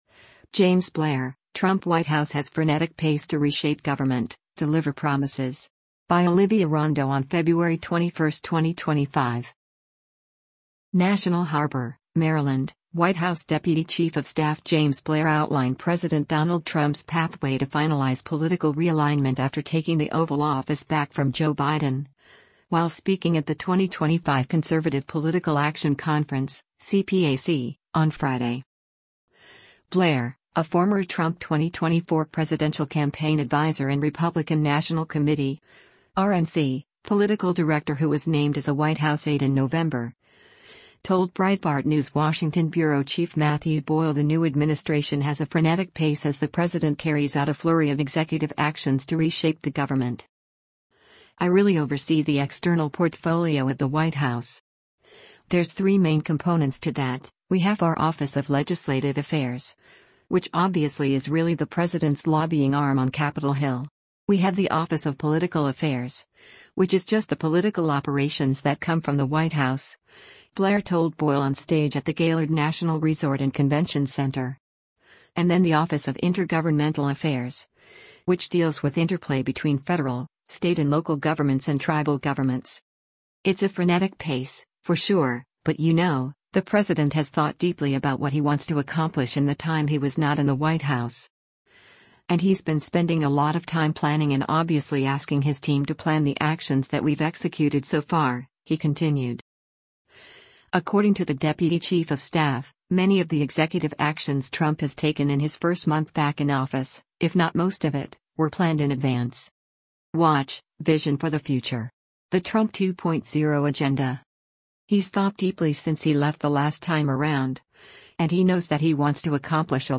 NATIONAL HARBOR, Maryland — White House Deputy Chief of Staff James Blair outlined President Donald Trump’s pathway to finalize political realignment after taking the Oval Office back from Joe Biden, while speaking at the 2025 Conservative Political Action Conference (CPAC) on Friday.